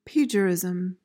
PRONUNCIATION:
(PEJ-uh-riz-uhm)